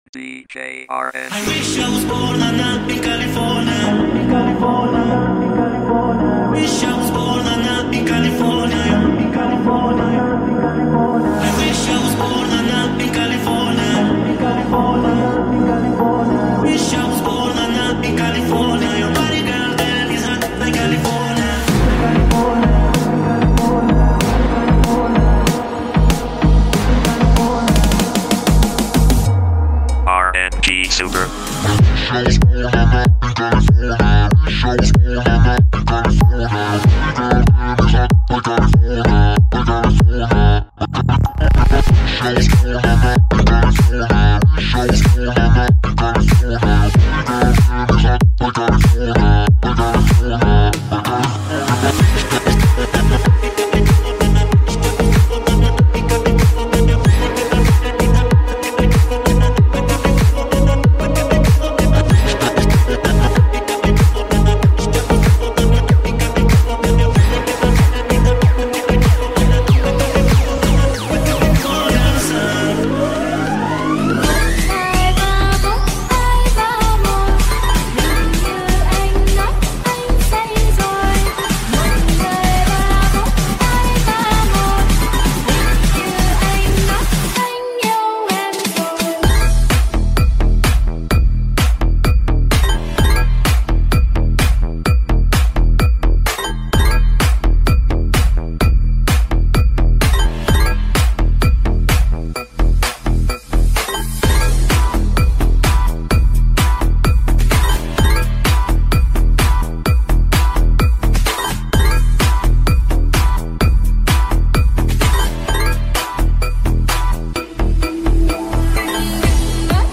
High quality Sri Lankan remix MP3 (5.5).